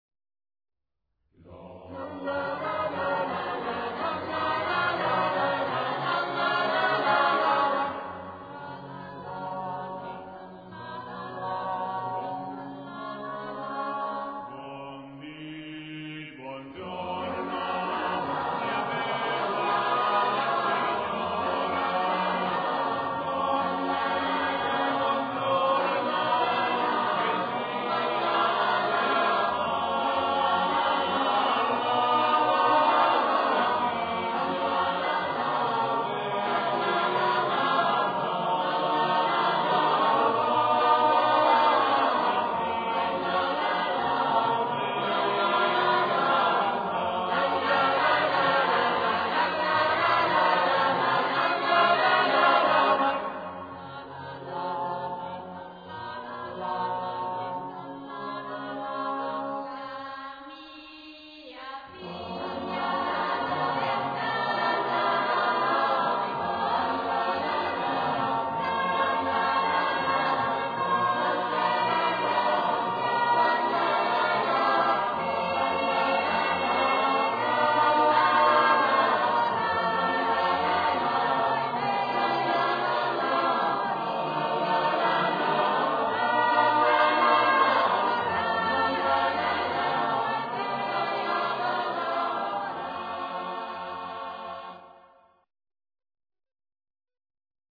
Musica > Canti
[ voci miste ]